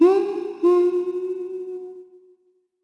Isaiah-Vox_Hum_r_jp.wav